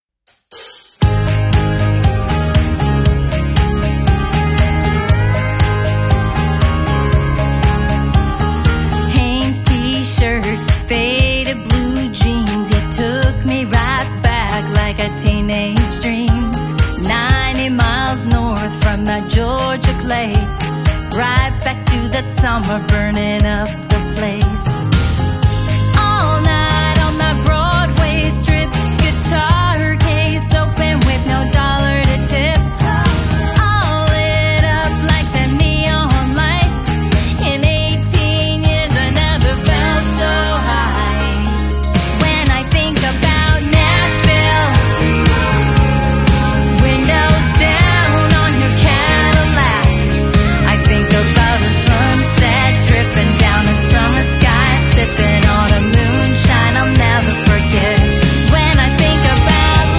90s country sound